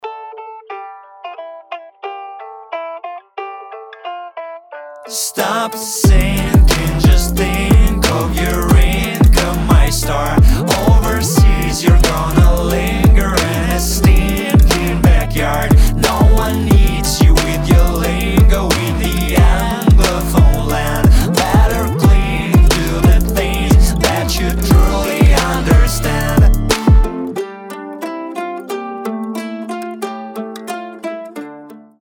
Громкие Mashup Cover
Alternative metal Рэп-рок Nu metal